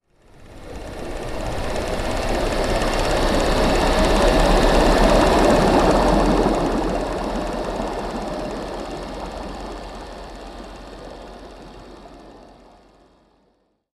Подводный взрыв торпеды вдали